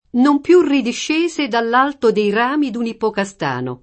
ippocastano [ippokaSt#no; alla lat. ippok#Stano] s. m. — es.: Non più ridiscese dall’alto dei rami d’un ippocastano [